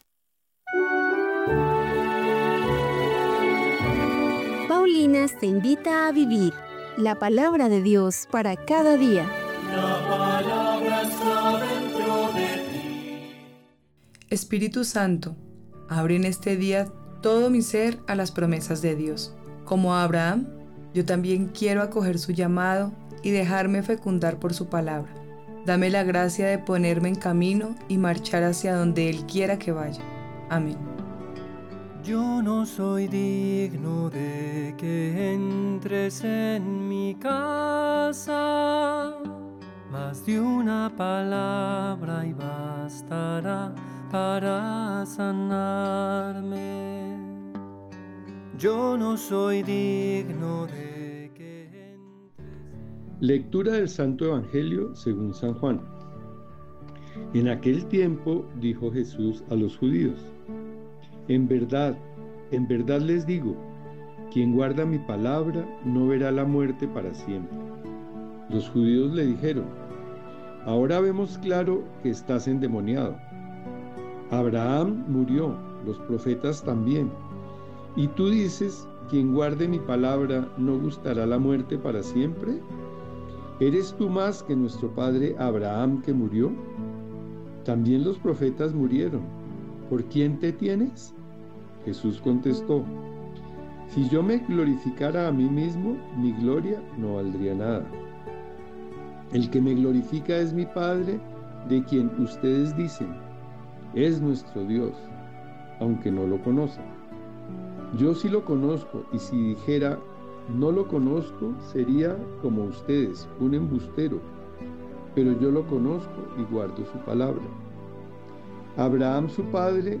Lectura de los Hechos de los Apóstoles 5, 17-26